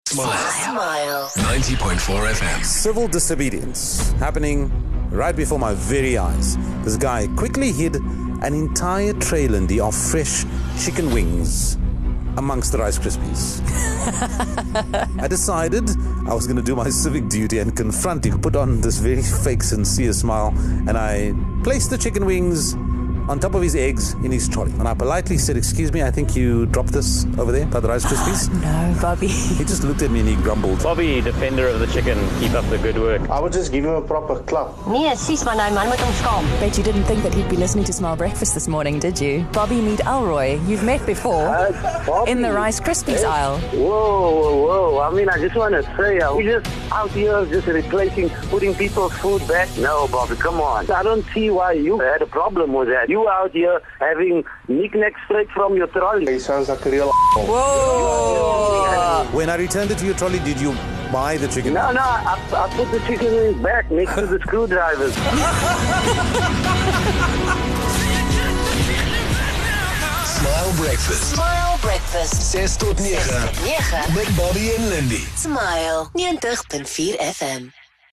16 Sep A listener confronts Smile Breakfast on air